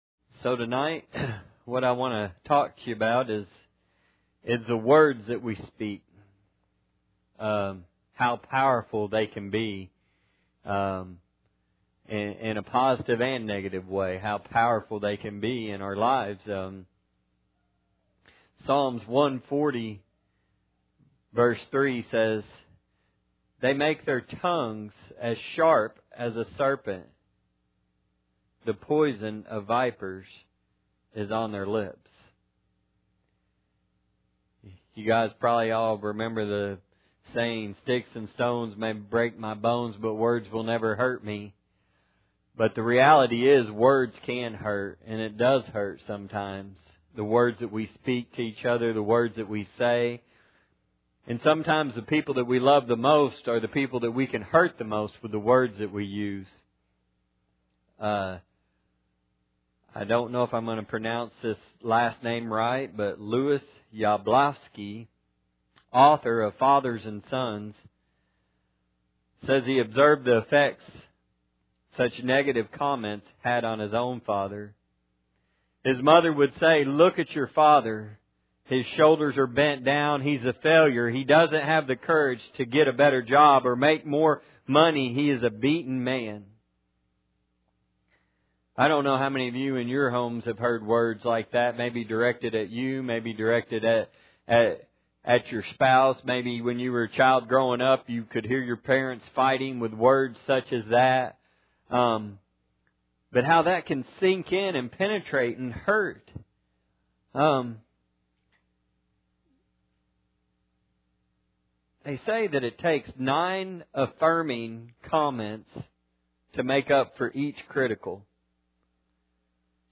Service Type: Sunday Night